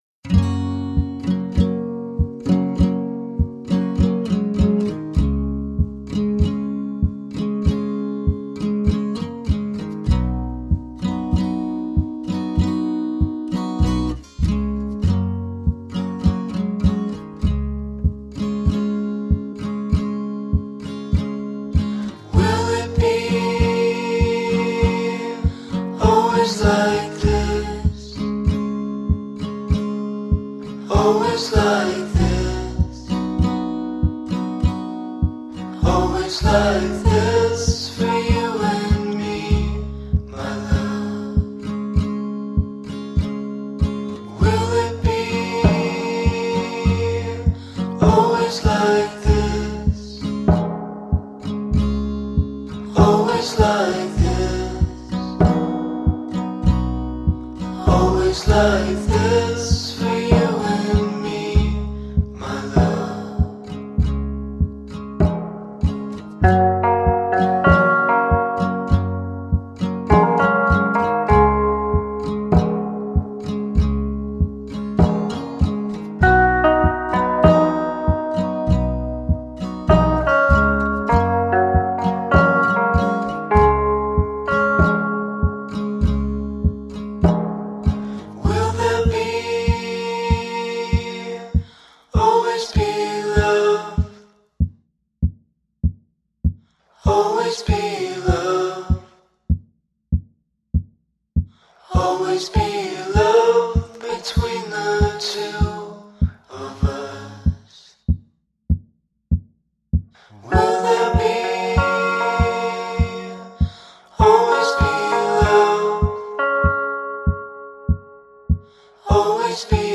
Melody-driven indie-folk.
Tagged as: Alt Rock, Folk-Rock, Chillout, Indie Rock